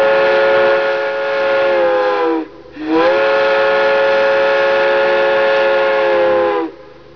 Trainhorn
TrainHorn.wav